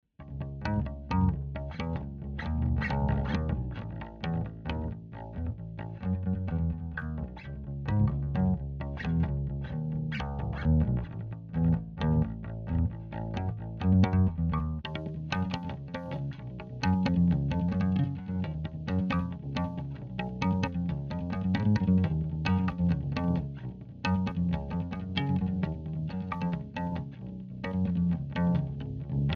Записал нашего басиста